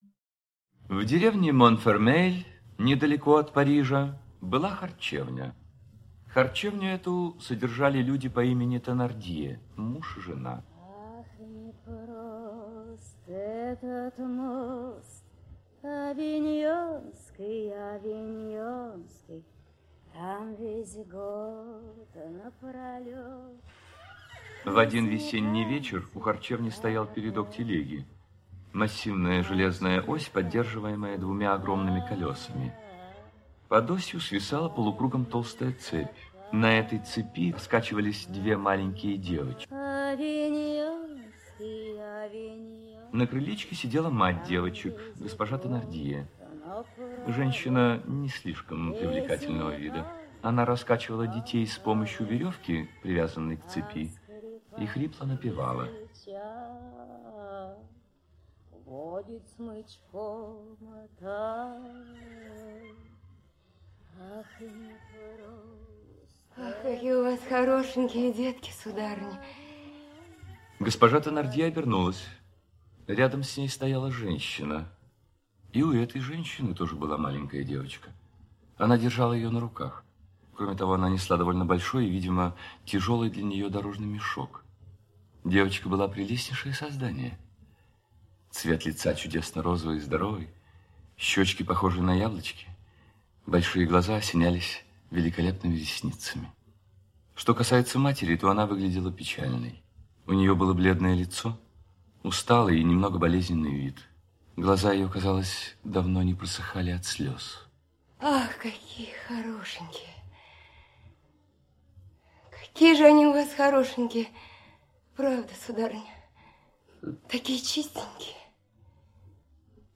Слушать онлайн аудиокнигу "Козетта":